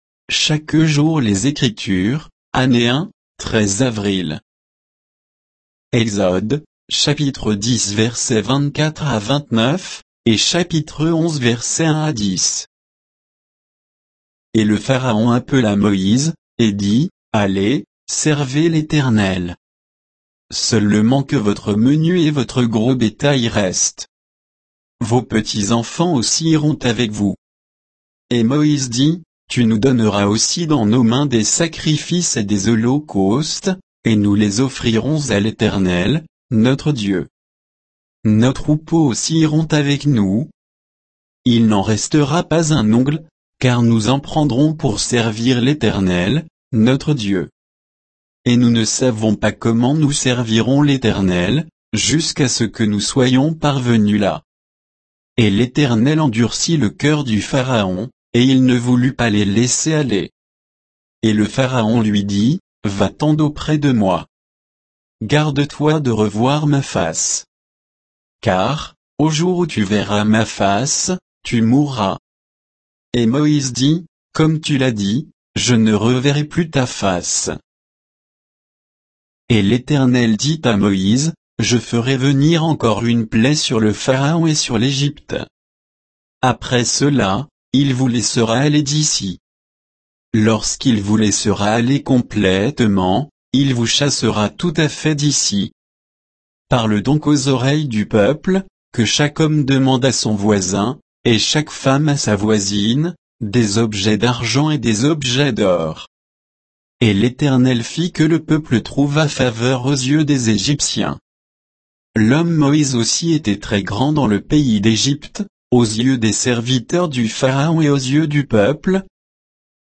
Méditation quoditienne de Chaque jour les Écritures sur Exode 10, 24 à 11, 10